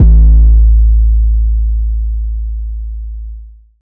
TAB_A_808_02.wav